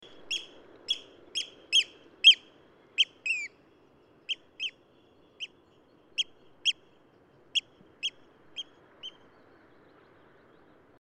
Eurasian Oystercatcher Haematopus ostralegus
PFR00125, 090603, Eurasian Oystercatcher Haematopus ostralegus excitement call, Runde, Norway